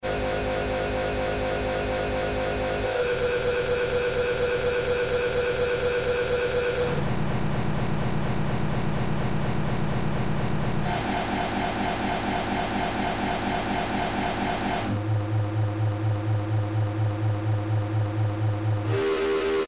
Glitch-sound-effect.mp3